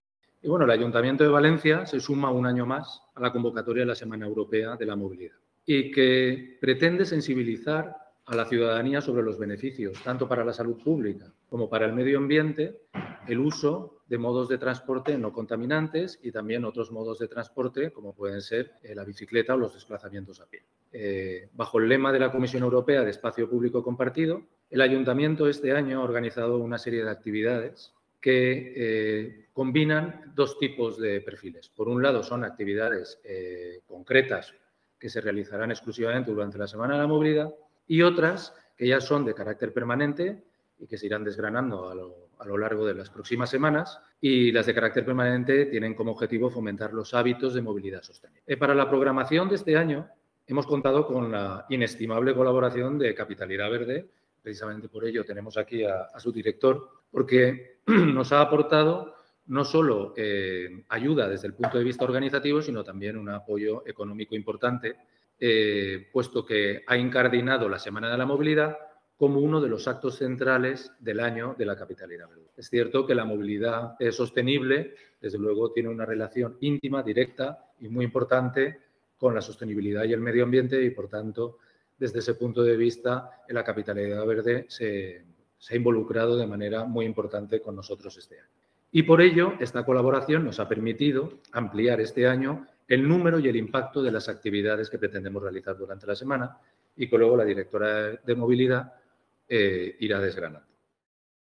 Con el tema ‘Espacio público compartido’, el concejal delegado de Seguridad y Movilidad, Jesús Carbonell, ha dado cuenta este jueves de la serie de actividades previstas por el Ayuntamiento de València con motivo de la Semana Europea de la Movilidad, que se desarrollará a lo largo y amplio de toda la ciudad entre el sábado 14 y el domingo 22 de septiembre.